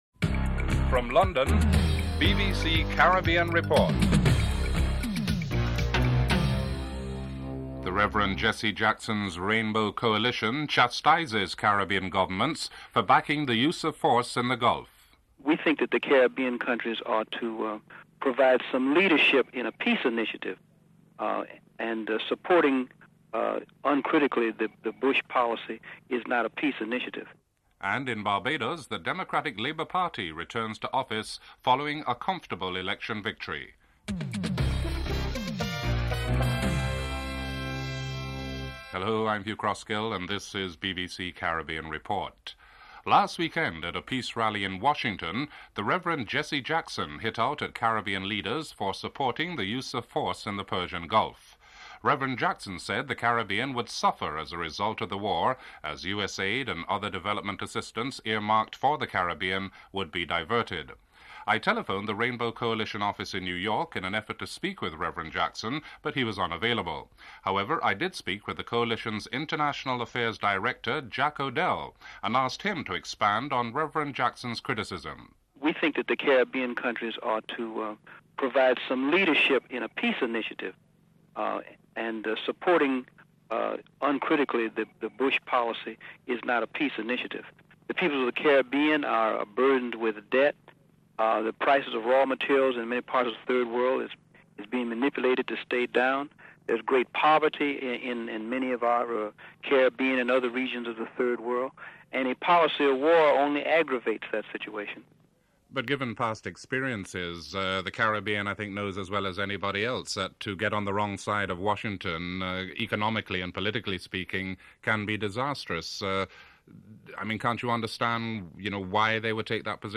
In segment 4 there is a short clip of "Beat them Sandy, beat them" - the rallying tune of the victorious Democratic Labour Party.
1. Headlines (00:00-00:37)